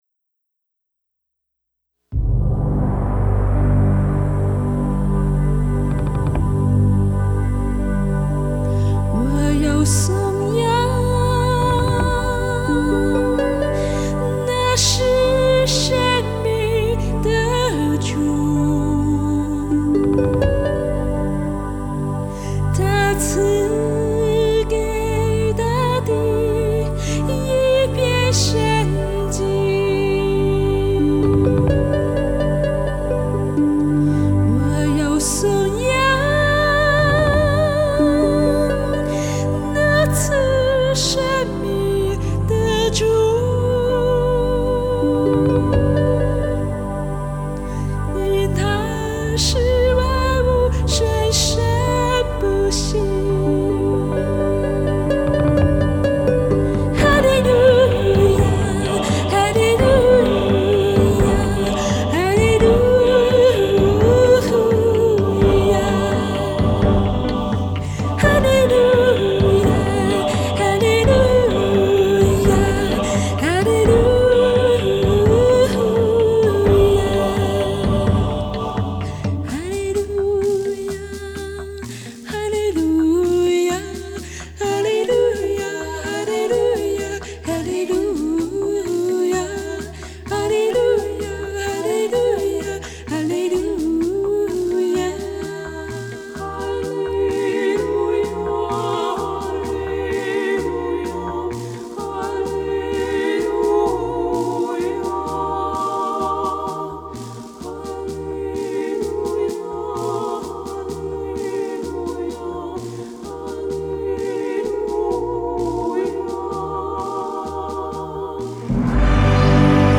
鋼琴、Midi演奏
錄音室：動物園錄音室